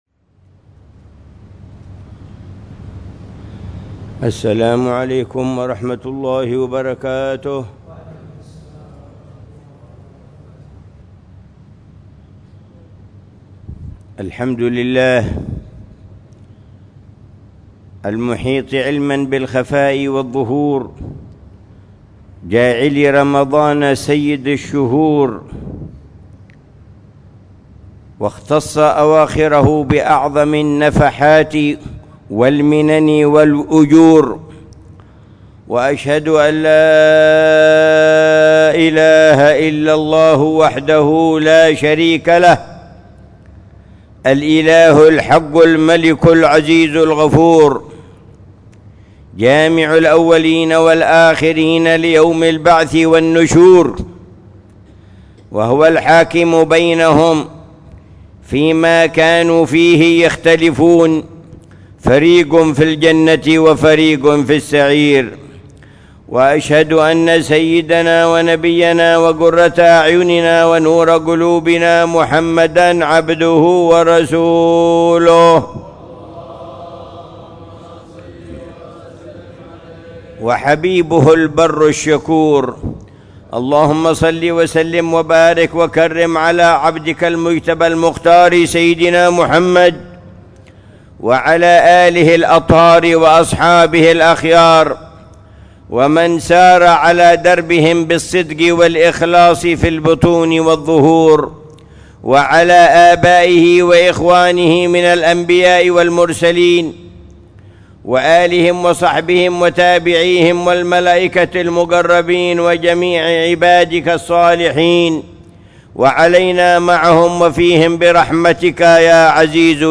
خطبة الجمعة للعلامة الحبيب عمر بن محمد بن حفيظ في جامع الروضة ، بحارة الروضة، عيديد، تريم، 21 رمضان 1446هـ بعنوان: